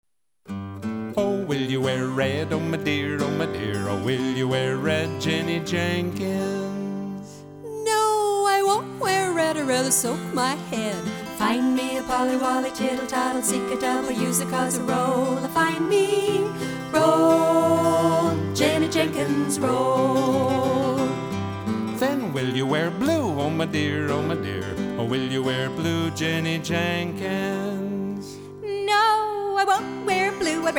clapping rhymes, playground games and traditional camp tunes